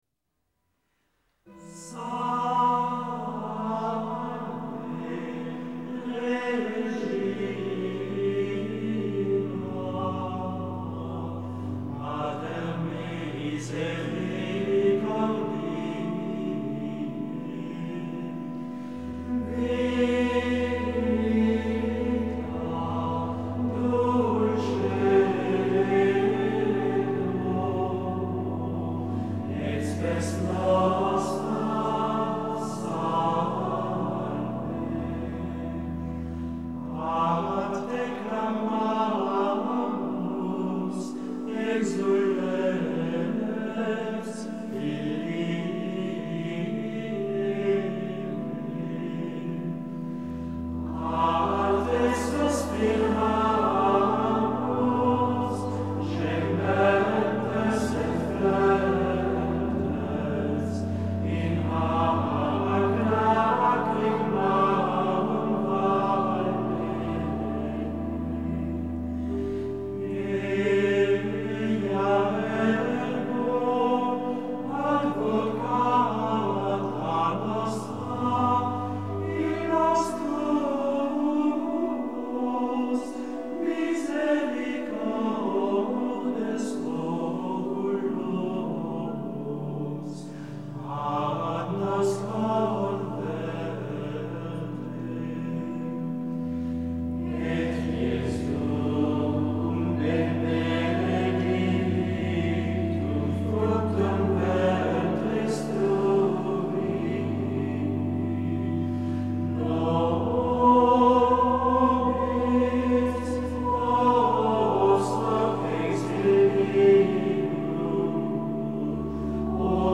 CHANTS GRÉGORIENS